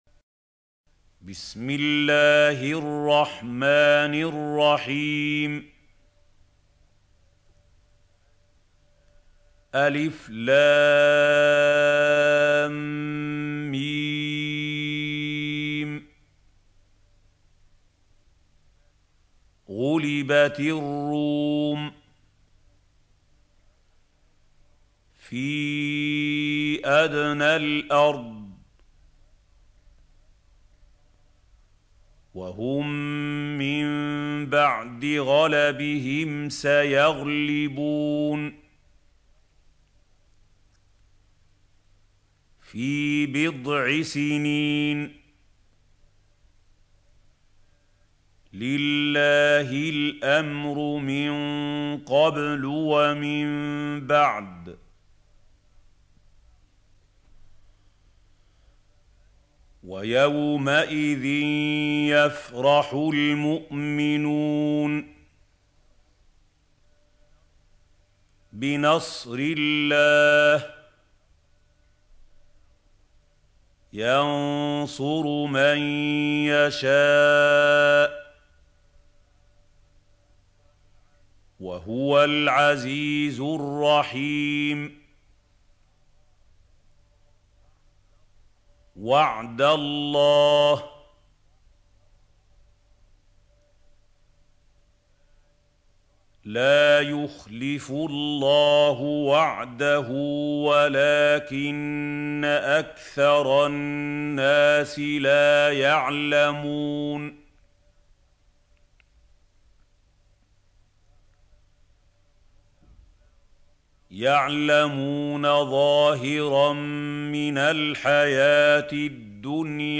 سورة الروم | القارئ محمود خليل الحصري - المصحف المعلم